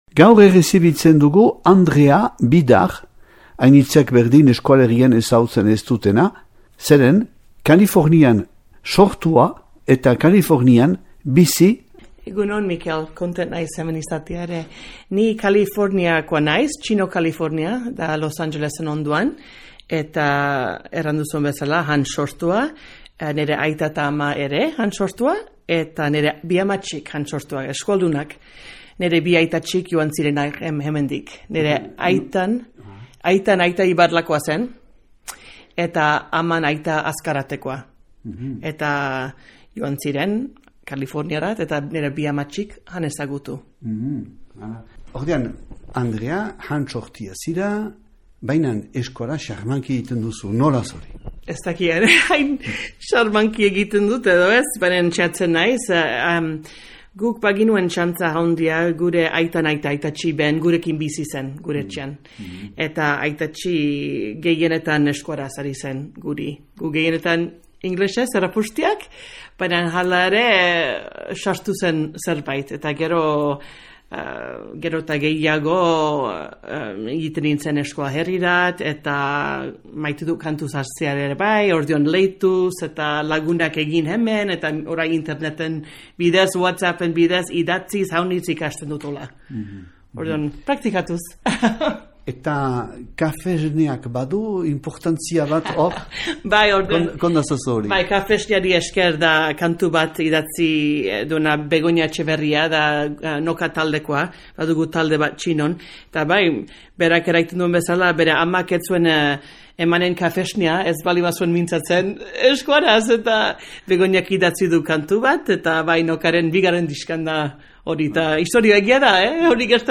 Elkarrizketak eta erreportaiak